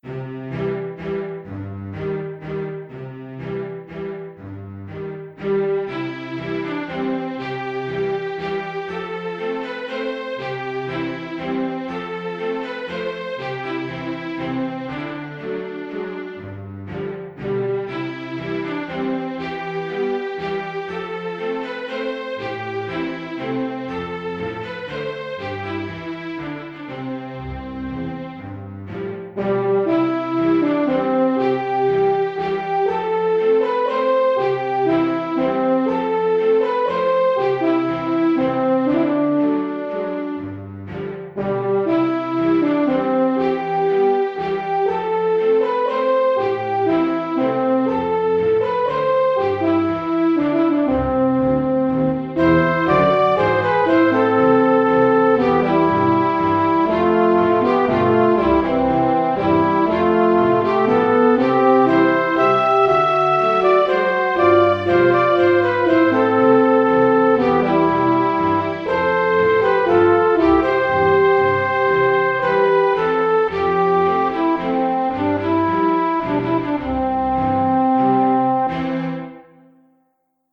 Electronic / 2009